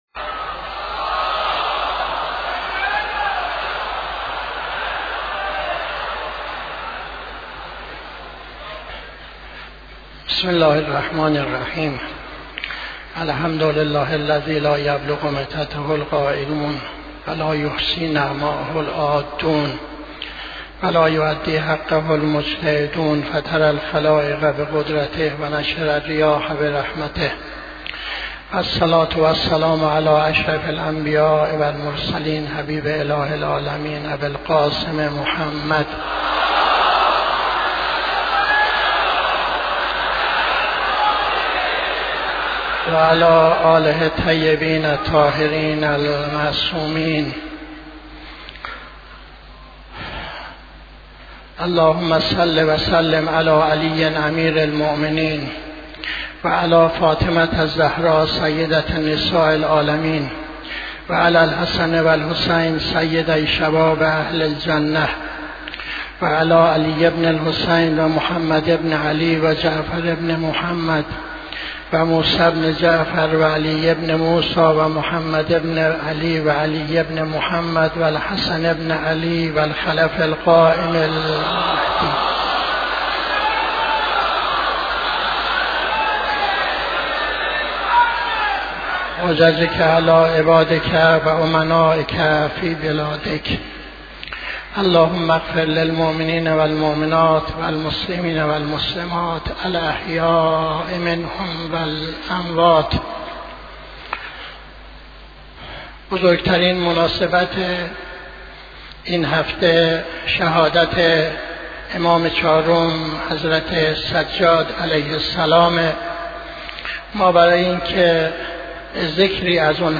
خطبه دوم نماز جمعه 09-01-82